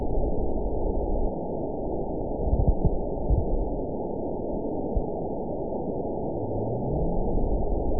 event 919870 date 01/26/24 time 16:49:29 GMT (1 year, 10 months ago) score 9.50 location TSS-AB04 detected by nrw target species NRW annotations +NRW Spectrogram: Frequency (kHz) vs. Time (s) audio not available .wav